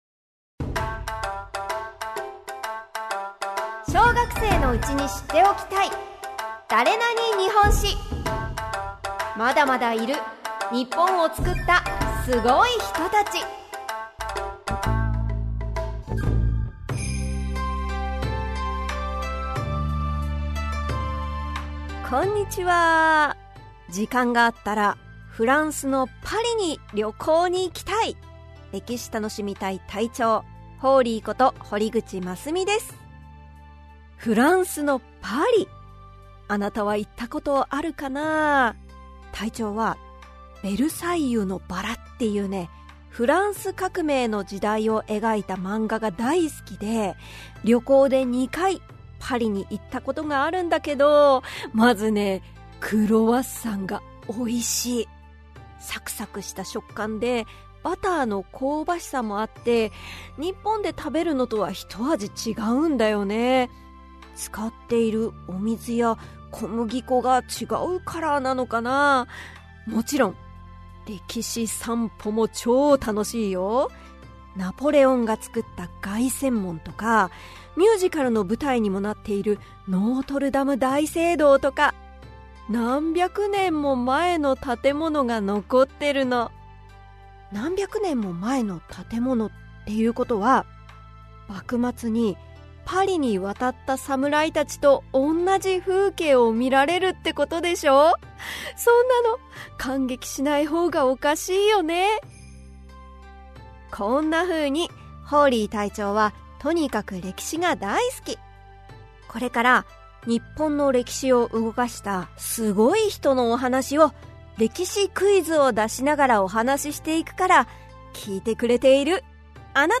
[オーディオブック] 小学生のうちに知っておきたい！だれなに？日本史 Vol.11 〜渋沢栄一〜